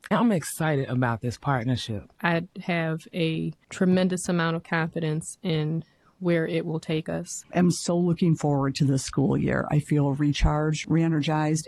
Kalamazoo Public Schools Board of Education meeting Aug. 31, 2023
At Thursday evening’s Board of Education meeting, several trustees, including Takisha Johnson, Tianna Harrison, and Jennie Hill mentioned that they are finally back to full strength.